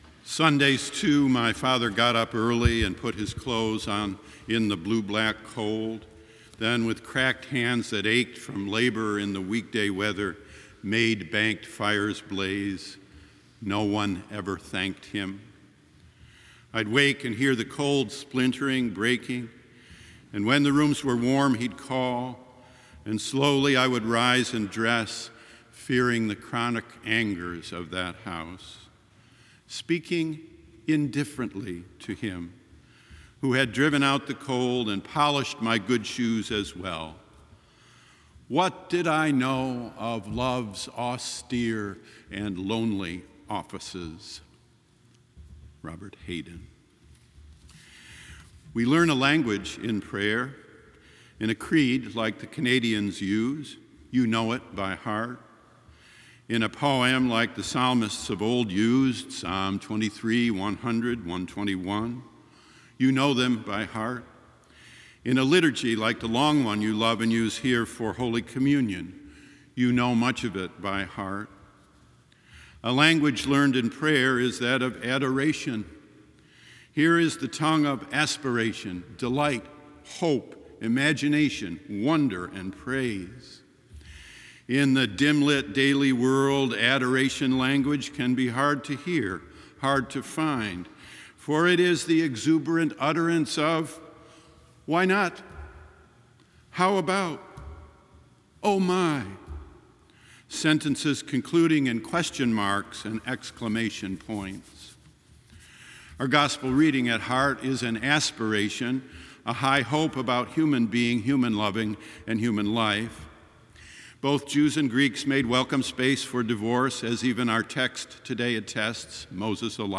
Communion Meditation